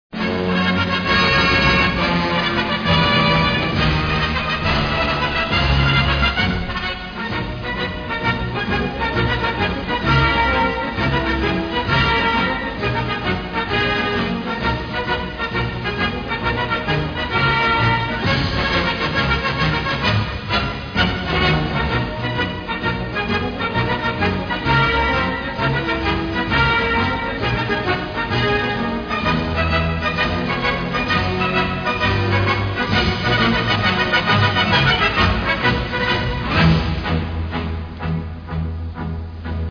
signature tune